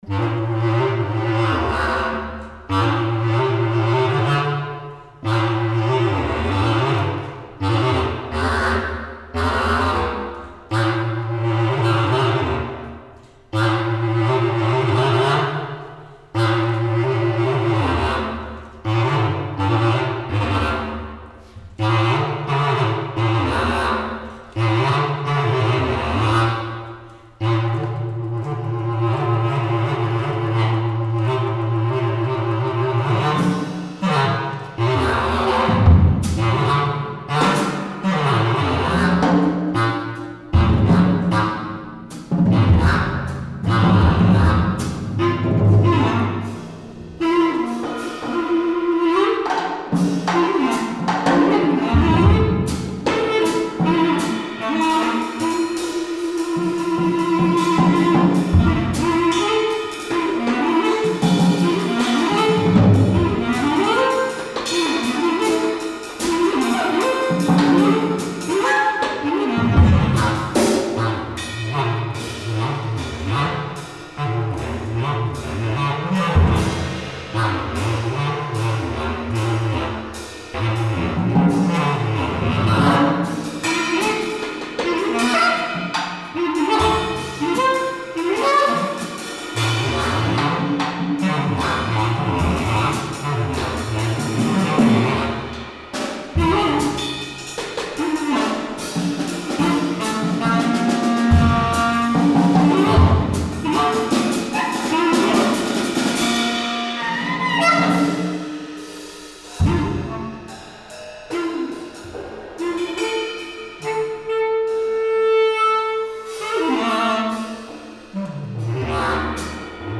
at the Banff Centre for the Arts